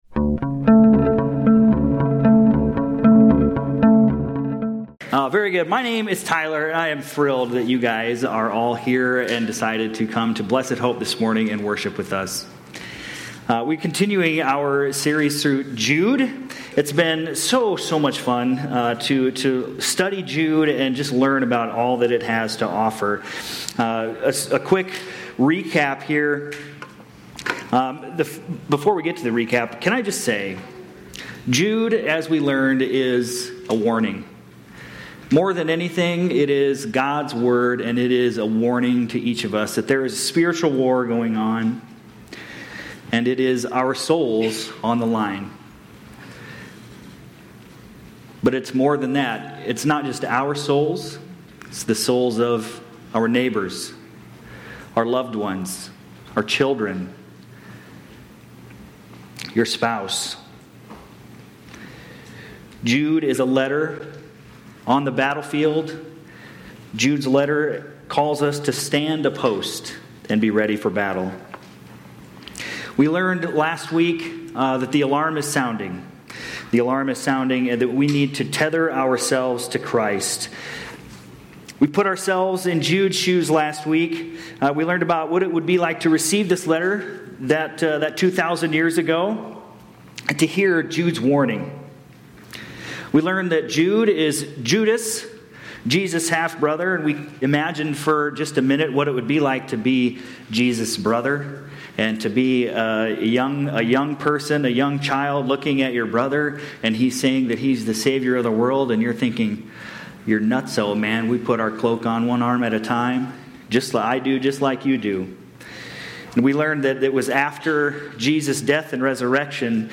Aug-17-25-Sermon-Audio.mp3